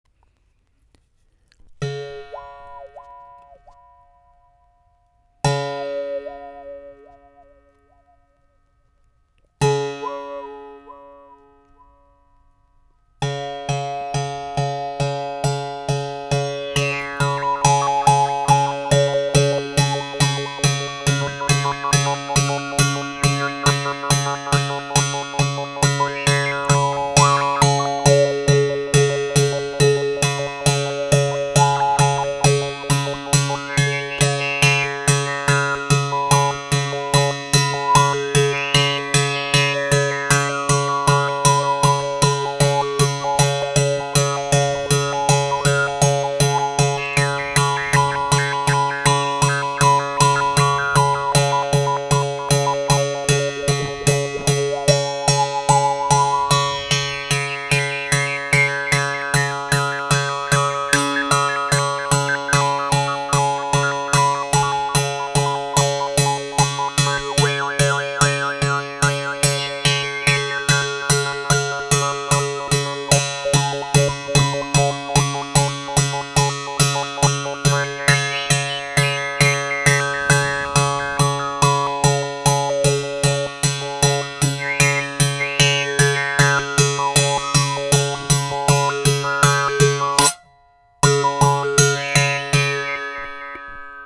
ARC EN BOUCHE 1 CORDE
Les sons proposés ici sont réalisés sans effet.
La baguette sera utilisée pour percuter la corde.